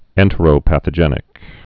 (ĕntə-rō-păthə-jĕnĭk)